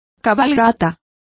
Complete with pronunciation of the translation of ride.